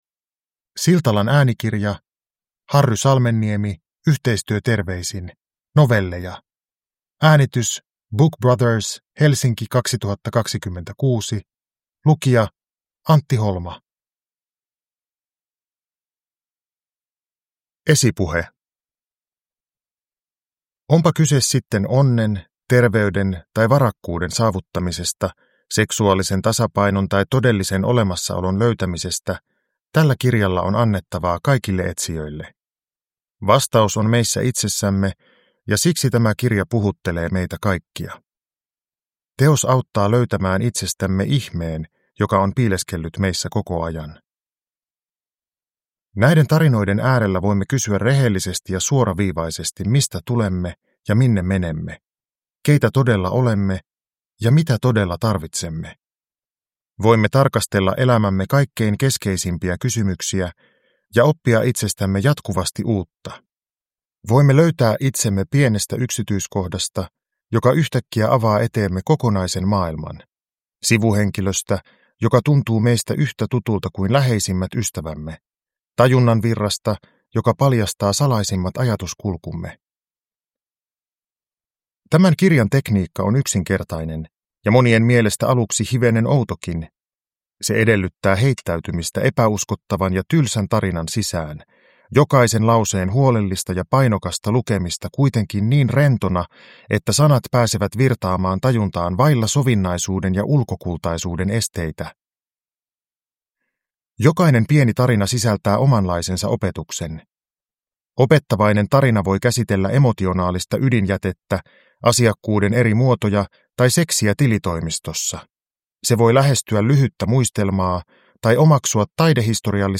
Yhteistyöterveisin – Ljudbok
Uppläsare: Antti Holma